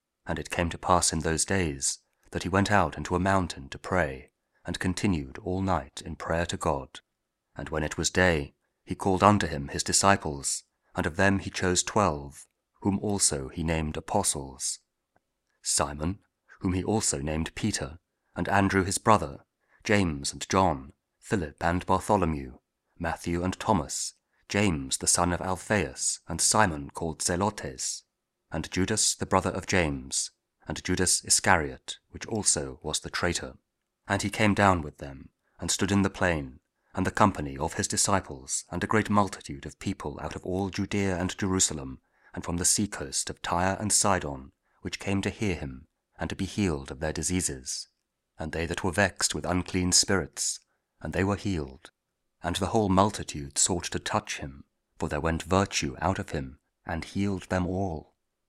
Luke 6: 12-19 – Week 23 Ordinary Time, Tuesday (King James Audio Bible  KJV, Spoken Word)